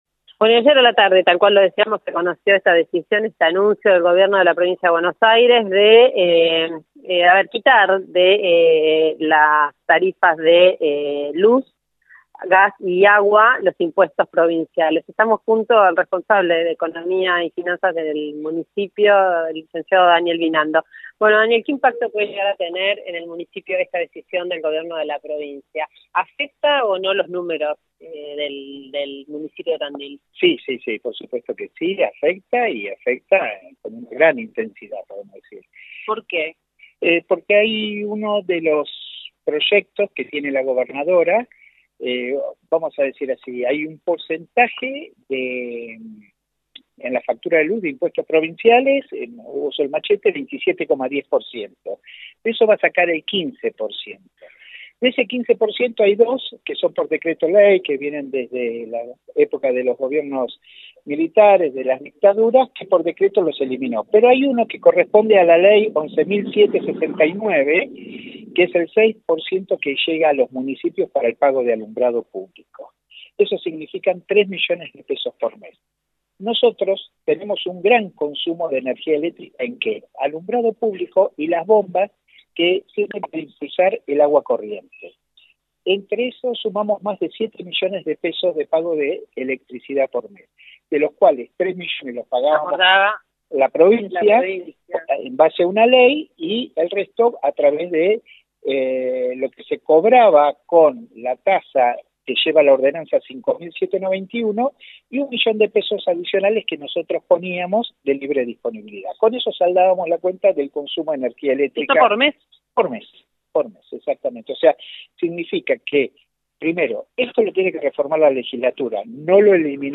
El responsable de Economía y Finanzas del Municipio, Lic. Daniel Binando, en comunicación con Tandil FM 104.1, habló sobre la eliminación de impuestos anunciada este lunes por la gobernadora Vidal.